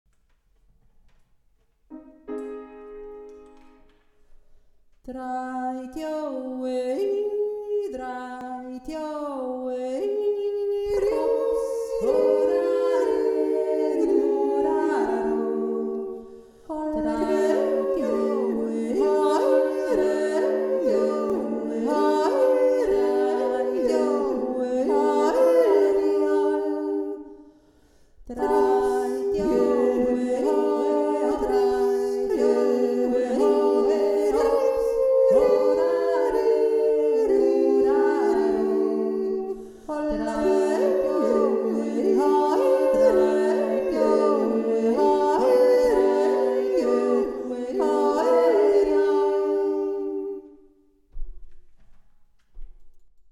Drei ho e i - der dreistimmige Jodler  (anklicken)
Der Jodler muss klar und präzise sein, auch nicht zu schnell, damit die einzelnen Stimmen gut hörbar sind. Es ist ein schönes Geflecht, die Stimmen weben sich ineinander...